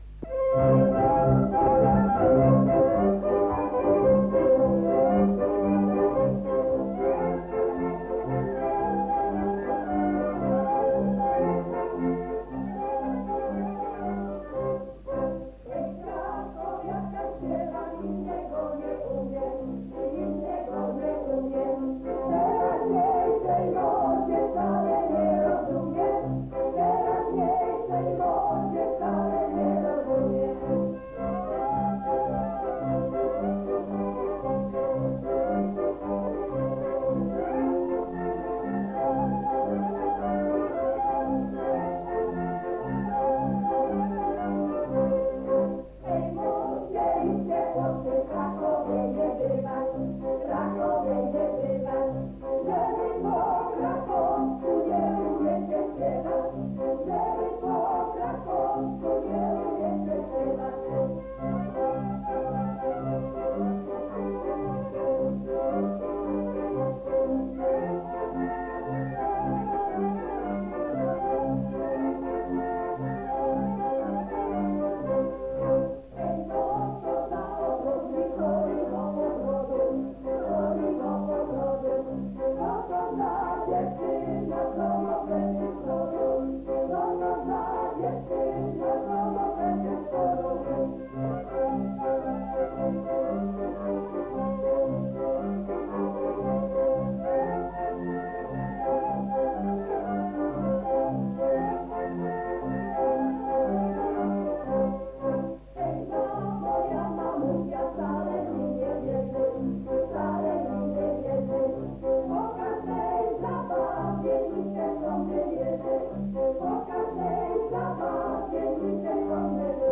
Nagranie archiwalne